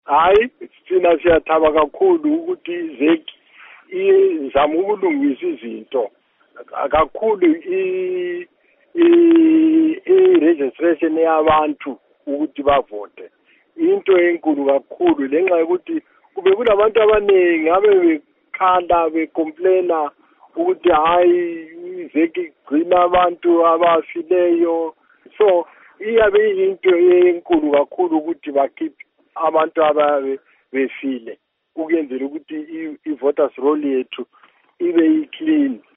Embed share Ingxoxo LoMnu Rugare Gumbo by VOA Embed share The code has been copied to your clipboard.